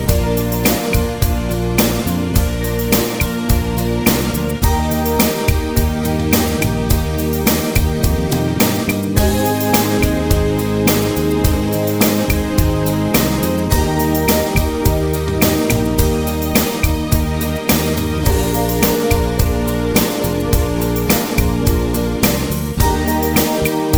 One Semitone Up Pop (1960s) 3:58 Buy £1.50